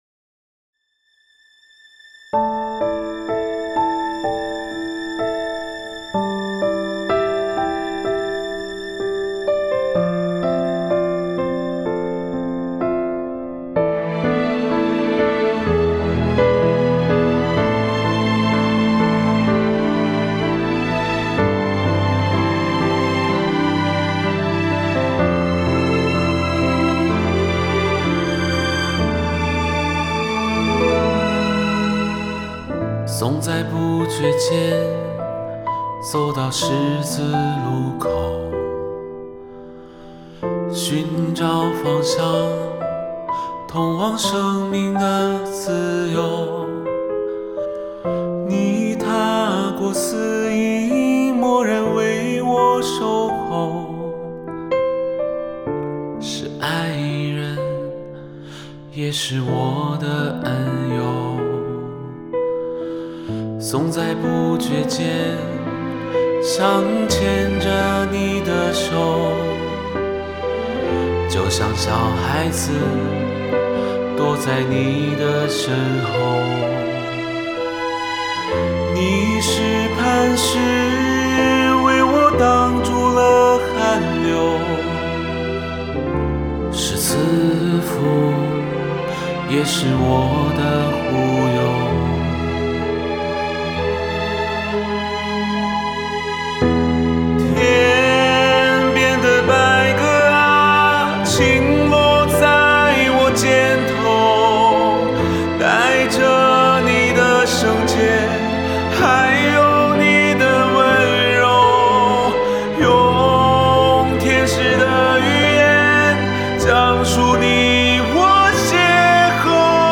于是，我决定以小调的方式去为这首歌作曲。
当进入到副歌部分时，采用呼求的表达方式，音阶上行去表述一遍遍的呼求和呐喊，高音部分强调全部身心的投入与感受。
最后，收回到小和弦，沉淀下来，去表达对那份圣爱的深沉体悟和感恩。